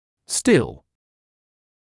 [stɪl][стил]все ещё, по-прежнему